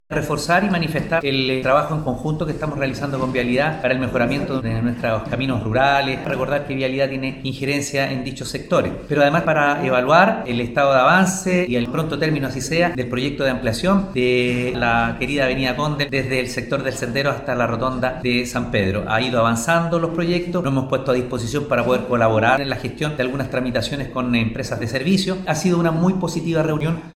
02-OSCAR-CALDERON-Alcalde-de-Quillota.mp3